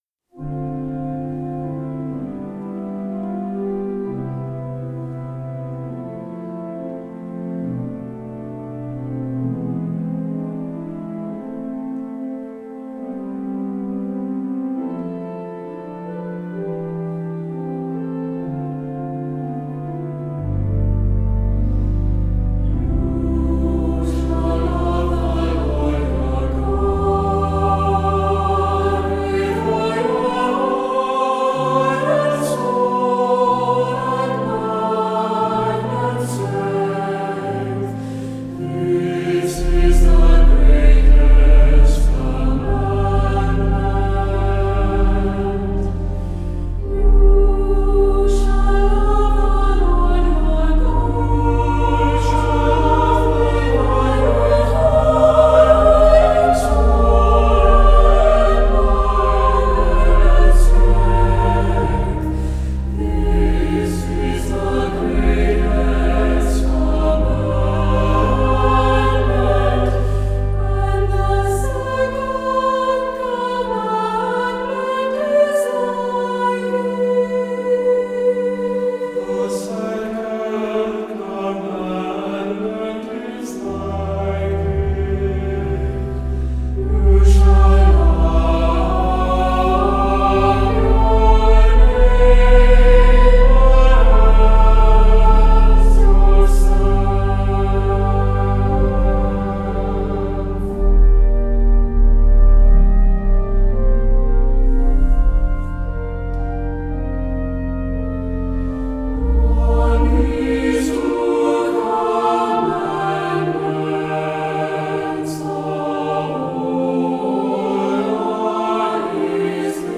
Voicing: SAB and Organ